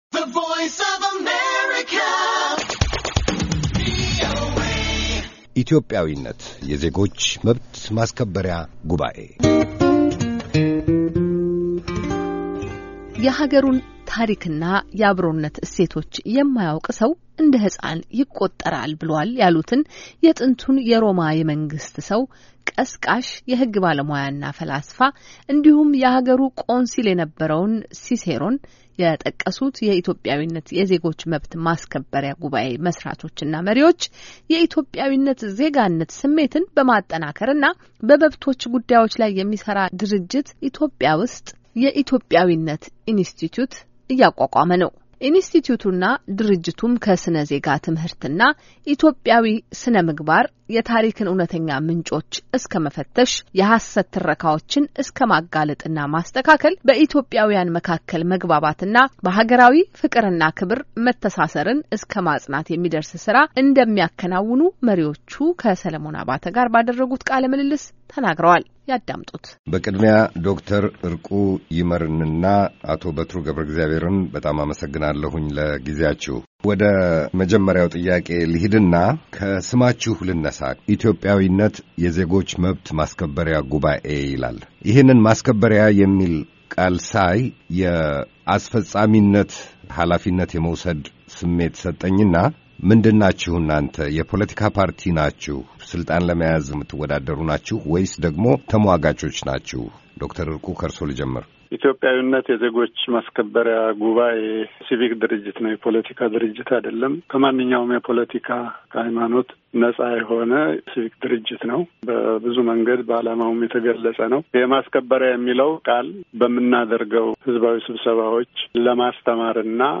የኢትዮጵያዊነት ኢንስቲትዩት እና ኢትዮጵያዊነት የዜጎች መብት ማስከበሪያ ጉባዔ ከሥነ-ዜጋ ትምህርትና ኢትዮጵያዊ ሥነ-ምግባር የታሪክን እውነተኛ ምንጮች እስከመፈተሽ፣ የሃሰት ትረካዎችን እስከማጋለጥና ማስተካከል፣ በኢትዮጵያዊያን መካከል መግባባትና በሃገራዊ ፍቅርና ክብር መተሣሰርን እስከ ማፅናት የሚደርስ ሥራ እንደሚያከናውኑ የድርጅቱና የተቋሙ መሥራቾችና መሪዎች ከአሜሪካ ድምፅ ጋር ባደረጉት ቃለ-ምልልስ ተናግረዋል።